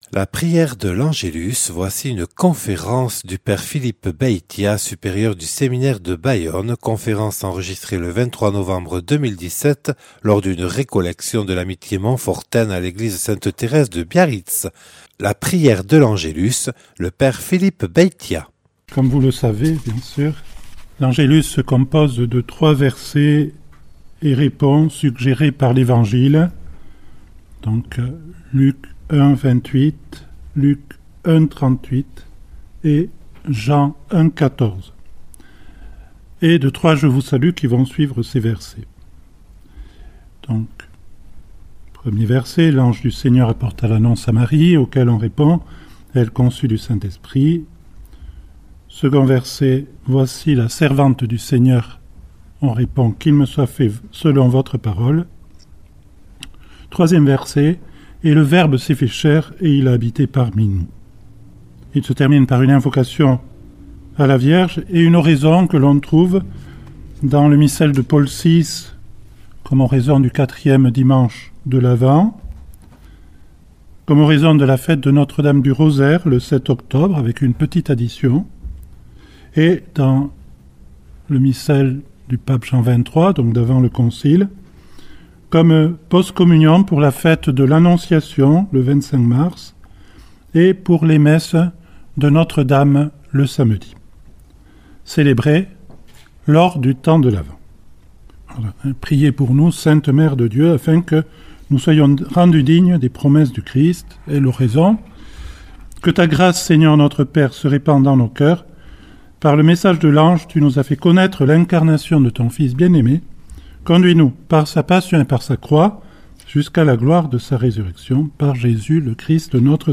Enregistré le 23/11/2017) lors d'une récollection de l'Amitié Montfortaine à l'église sainte Thérèse de Biarritz